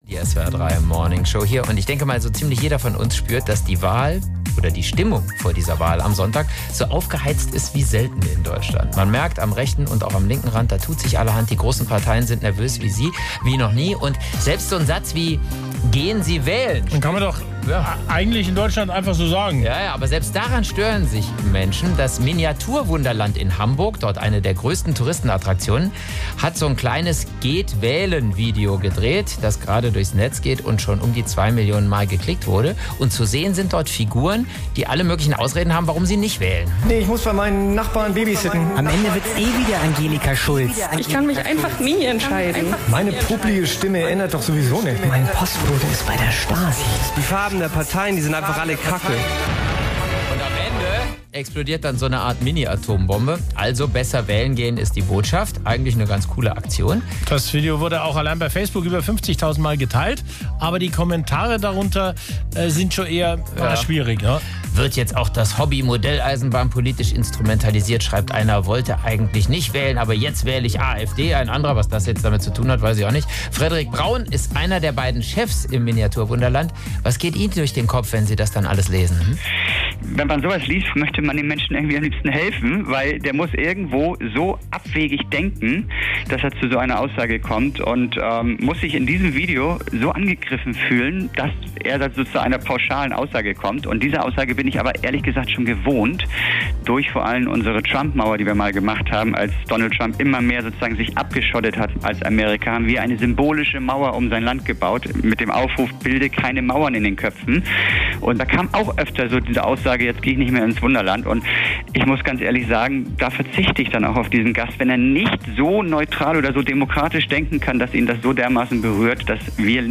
Interview SWR3 Morningshow